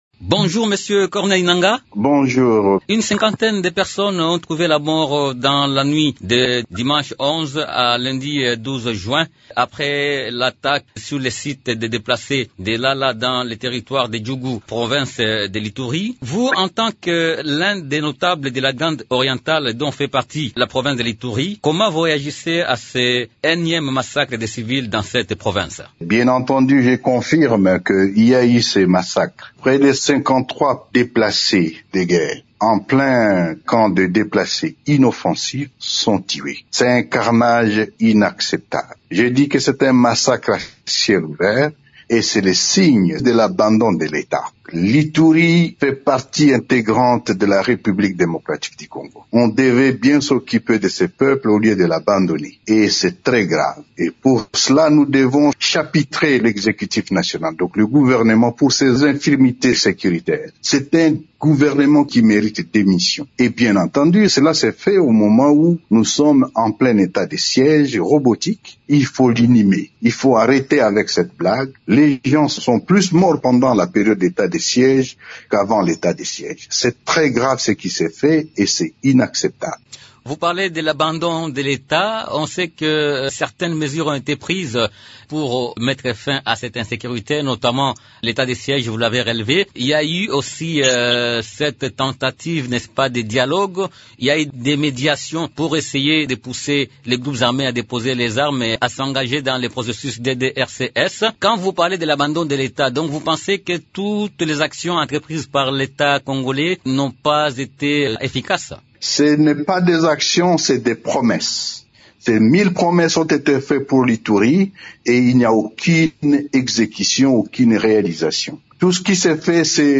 Invité de Radio Okapi ce jeudi 15 juin, Corneille Nangaa s’entretient